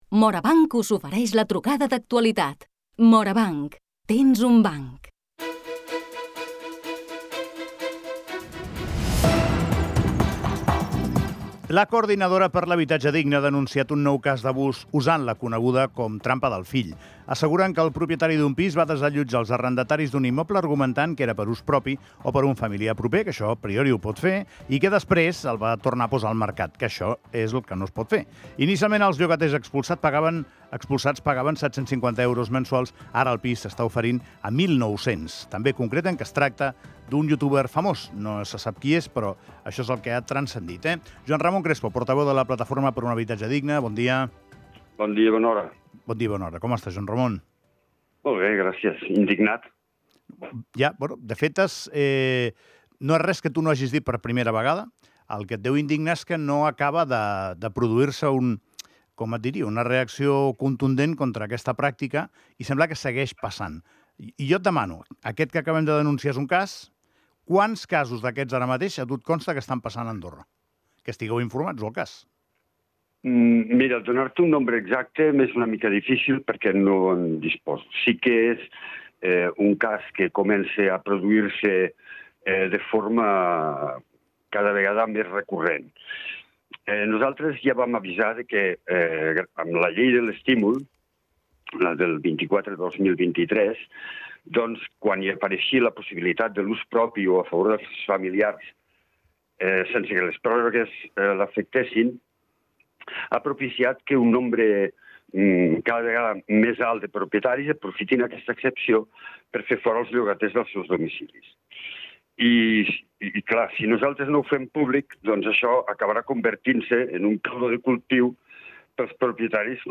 Trucada d'actualitat